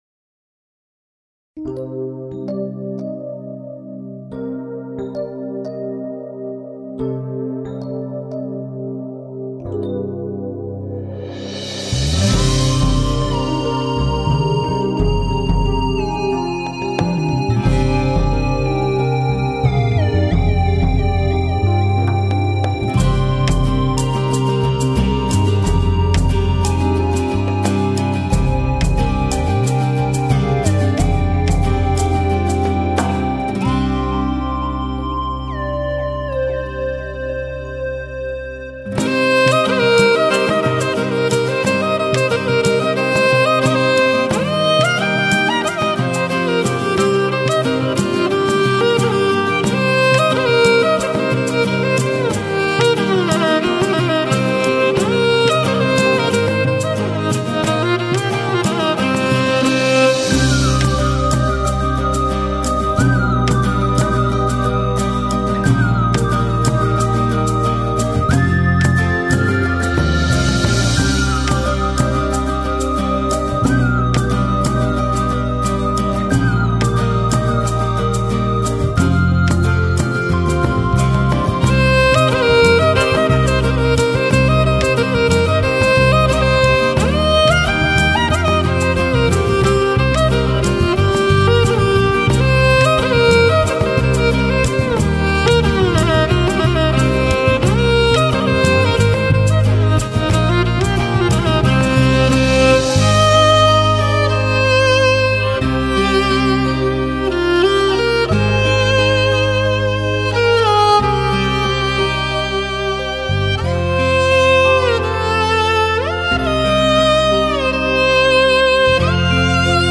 类型: 民乐雅韵
柔美纯正，旋律醇美，来自草原最深情的声音，让你心情爽朗！